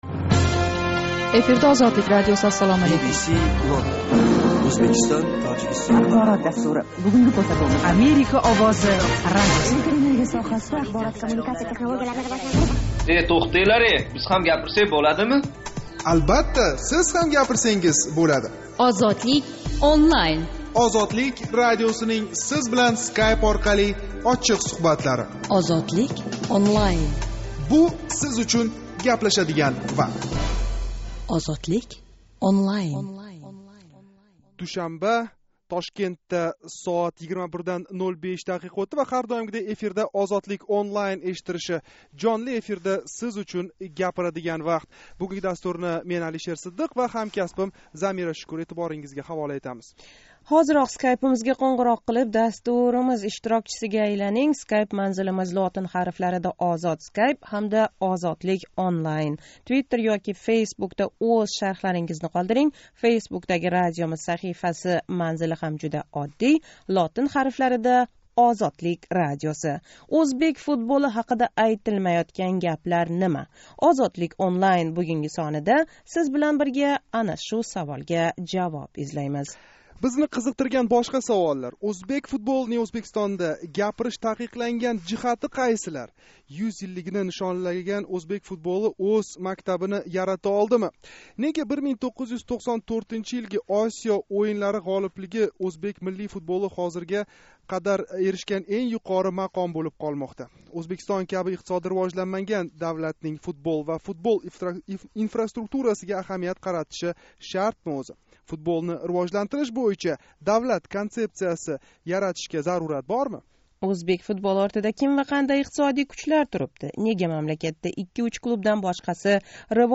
OzodlikOnline жонли¸ интерактив дастурининг 8 октябр¸ душанба куни Тошкент вақти билан 21:05 да бошланган янги сонида шу ҳақда гаплашдик.